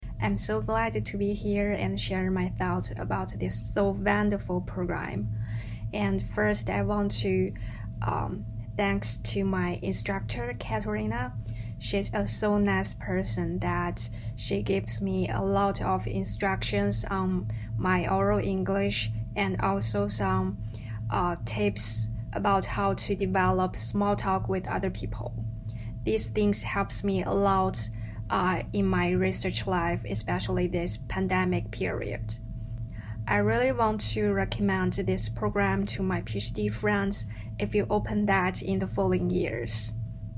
Testimonial 4